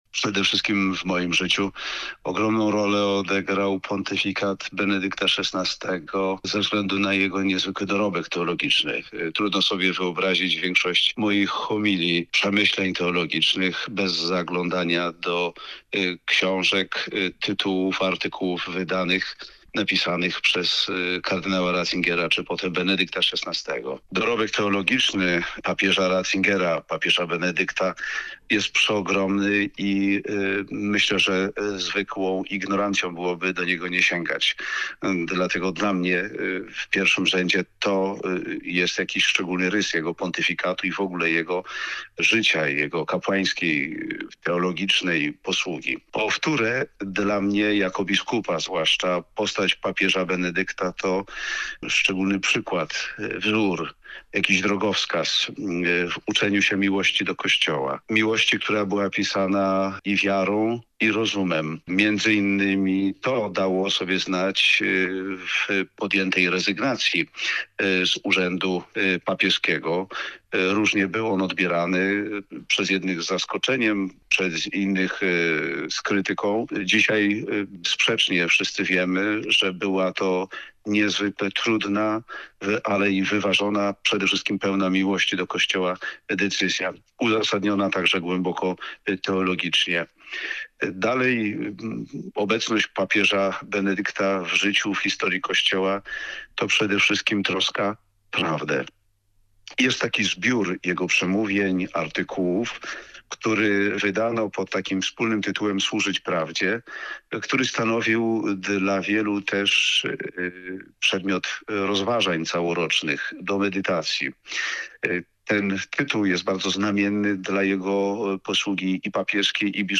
Posłuchaj wypowiedzi bp. Zbigniewa Zielińskiego